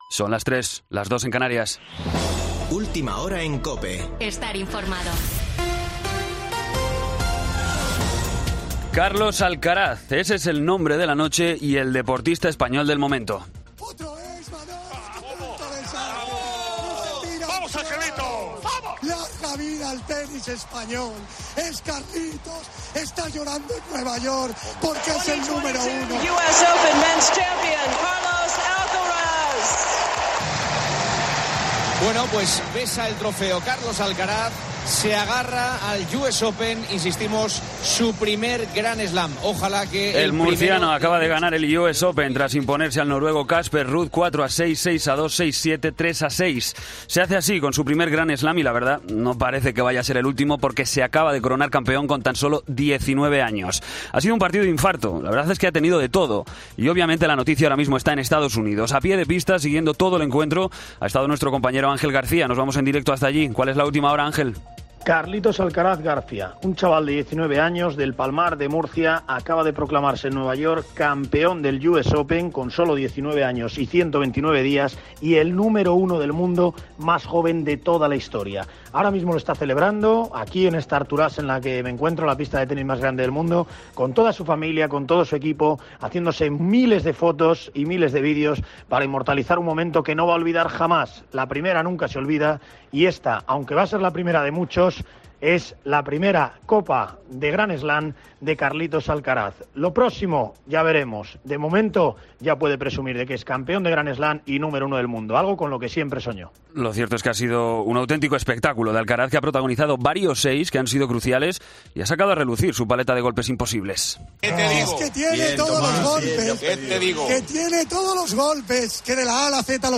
Boletín de noticias COPE del 12 de septiembre a las 03:00 horas